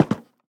Minecraft Version Minecraft Version 1.21.5 Latest Release | Latest Snapshot 1.21.5 / assets / minecraft / sounds / block / cherrywood_fence_gate / toggle3.ogg Compare With Compare With Latest Release | Latest Snapshot